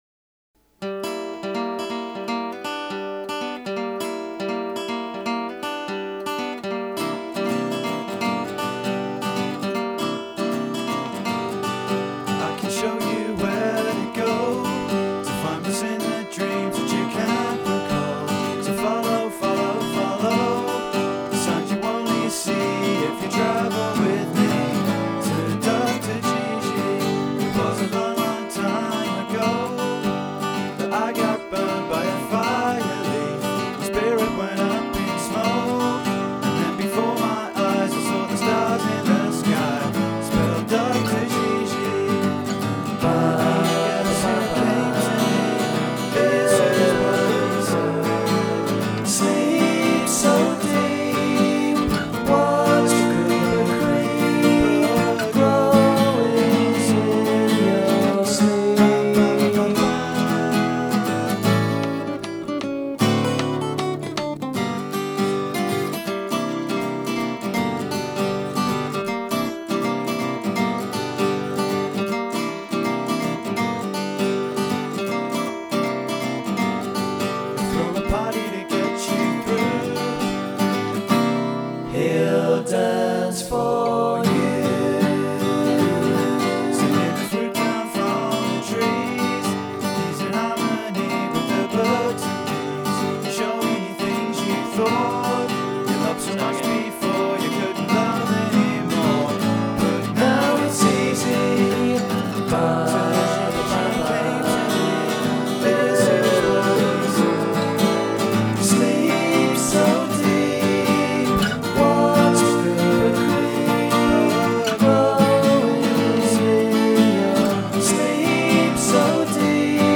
* Demo *